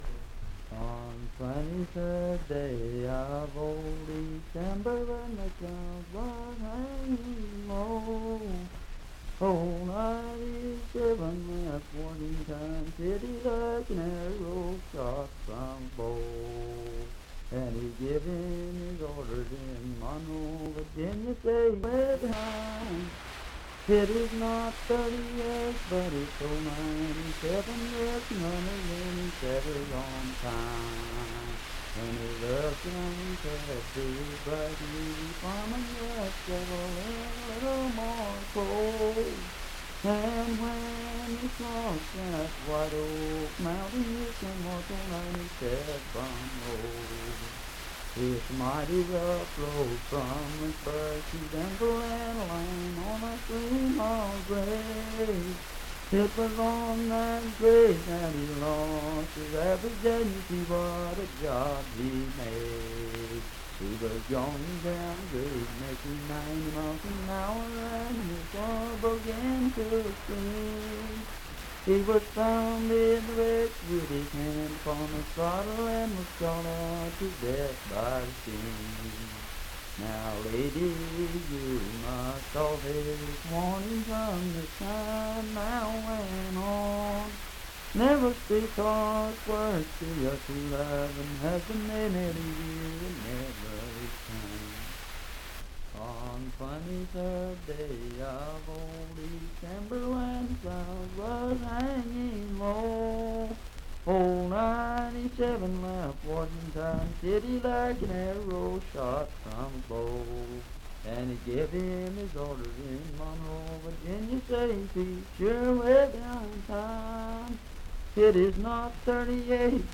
Unaccompanied vocal music
Voice (sung)
Franklin (Pendleton County, W. Va.), Pendleton County (W. Va.)